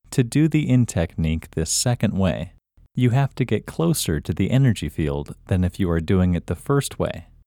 IN – Second Way – English Male 5